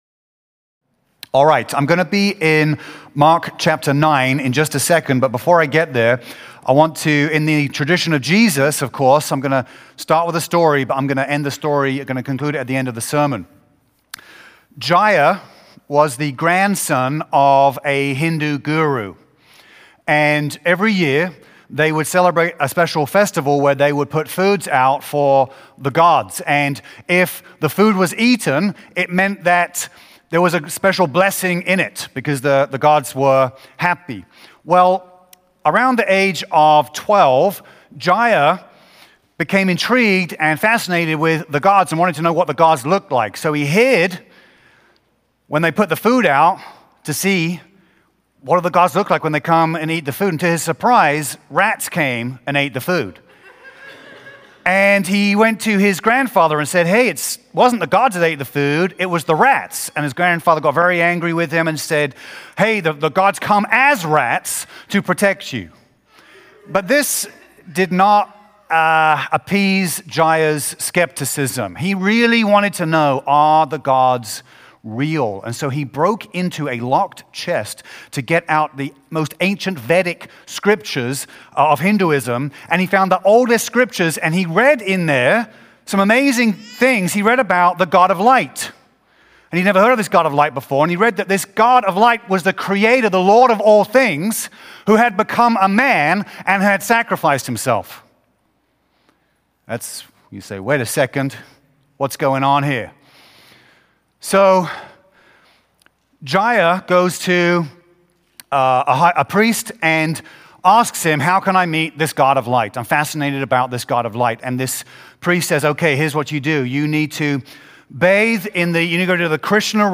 SERMONS
May-4-2025-FULL-SERMON.mp3